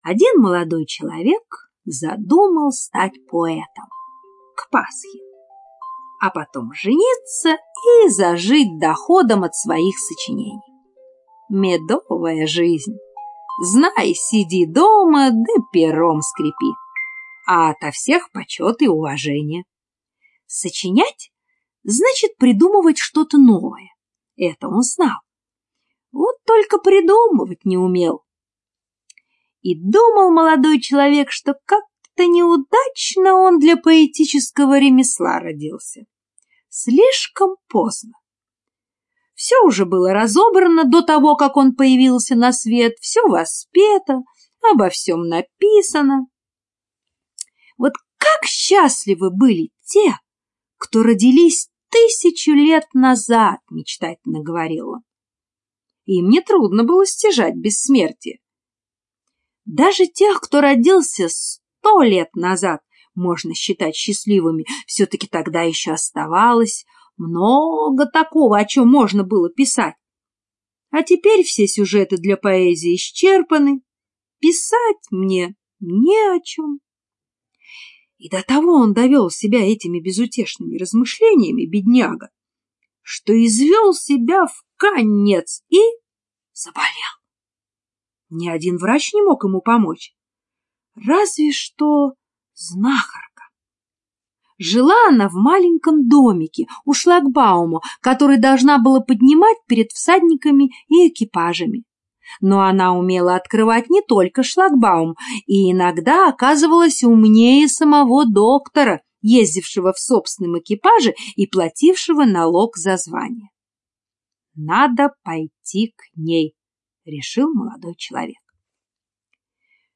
Чего только не придумают - аудиосказка Андерсена.